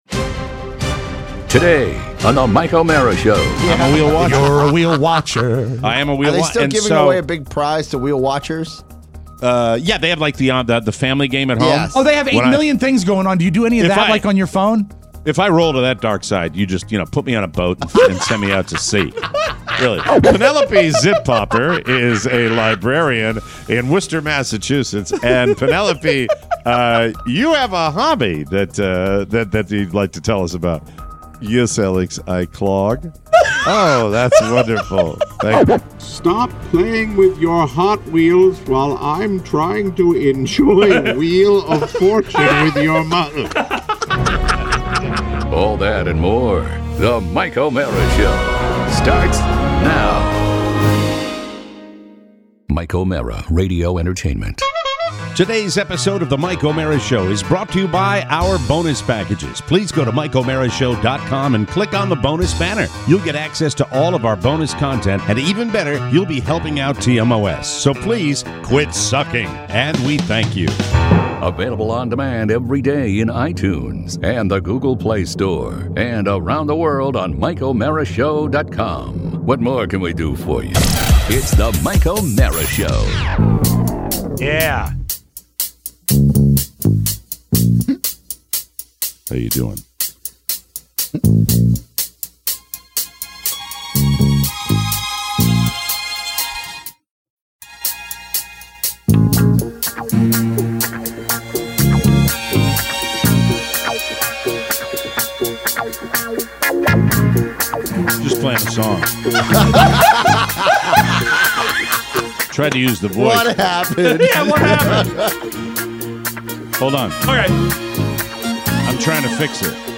Today, an in-studio tour by Mike O’Meara. Plus, your letters… Wheel of Misfortune… school lotteries… and teeth.